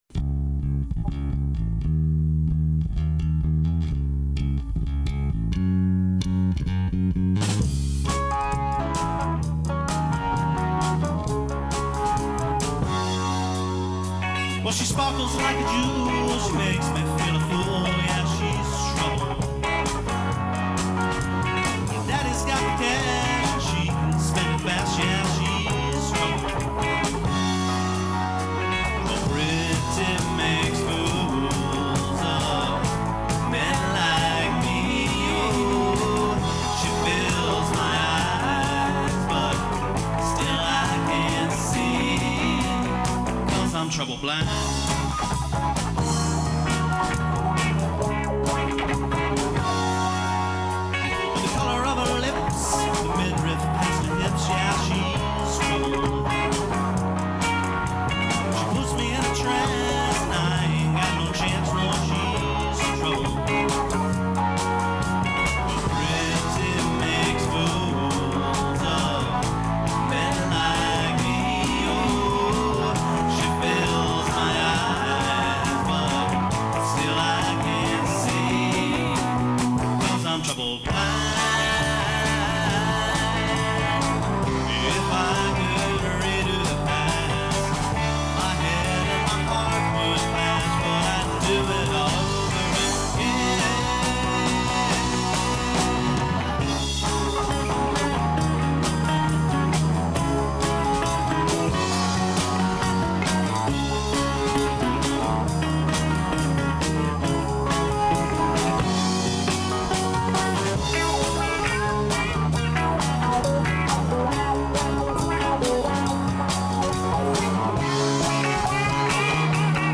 (live)
guitar